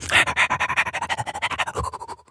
dog_sniff_breathe_02.wav